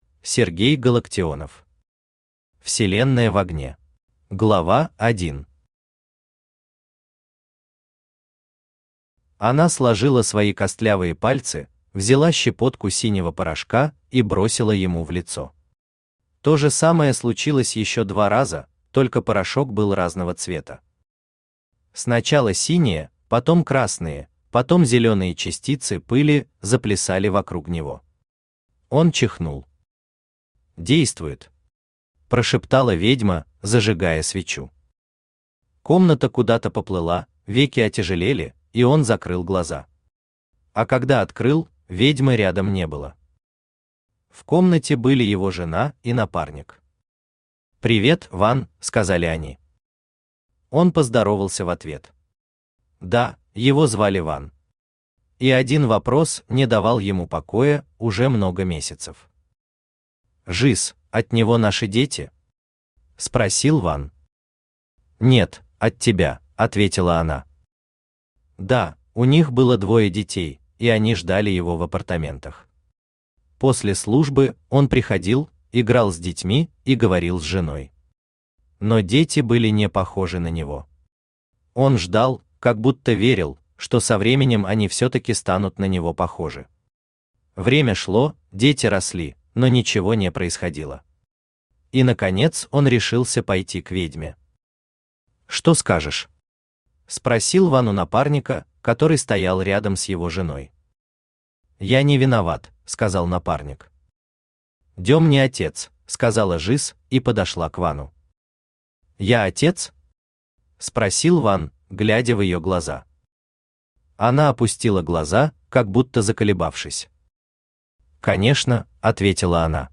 Аудиокнига Вселенная в огне | Библиотека аудиокниг
Aудиокнига Вселенная в огне Автор Сергей Валерьевич Галактионов Читает аудиокнигу Авточтец ЛитРес.